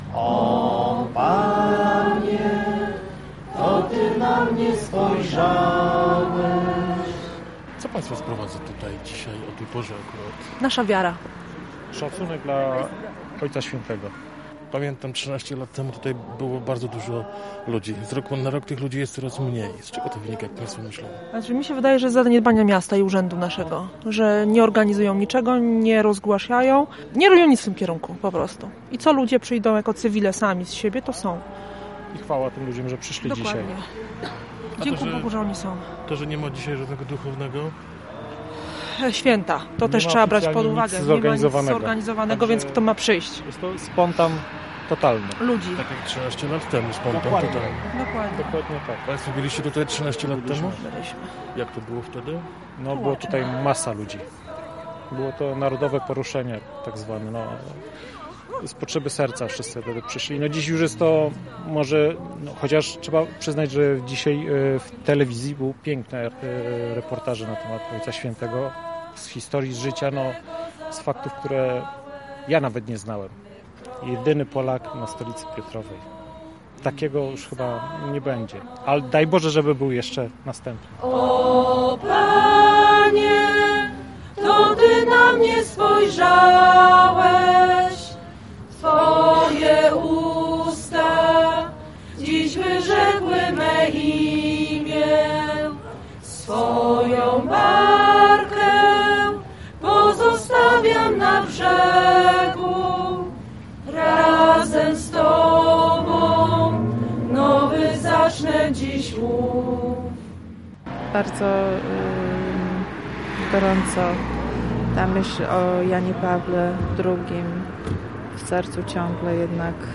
Kilkadziesiąt osób zgromadziło się przed pomnikiem św. Jana Pawła II, by uczcić 13. rocznicę śmierci papieża.